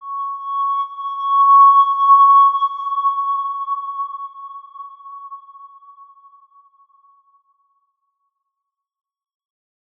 X_Windwistle-C#5-ff.wav